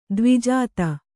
♪ dvijāta